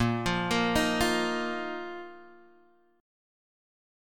A#sus4 chord